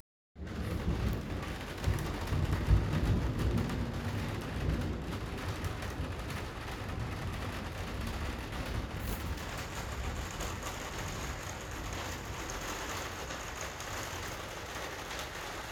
Un son faible dans la tempête
Un de nos agents nous a envoyé un message, mais la tempête couvre sa voix.